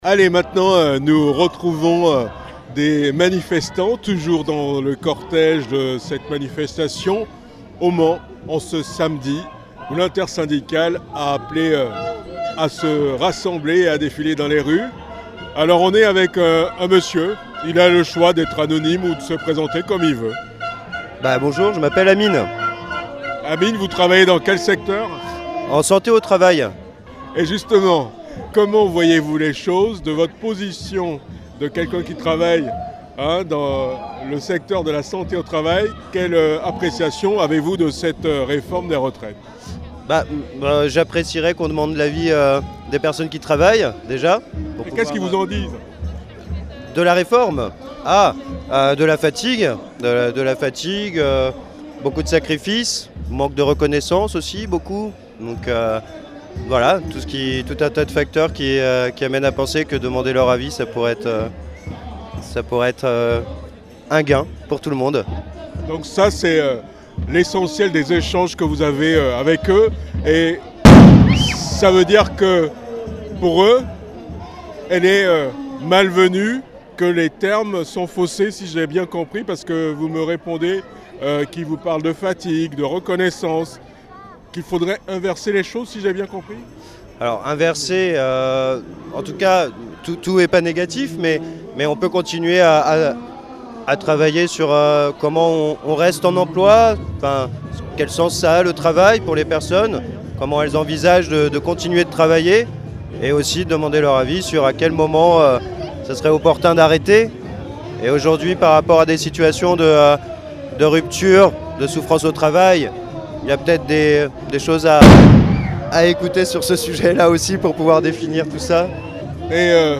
11 février 2023 : manifestation contre la réforme des retraites au Mans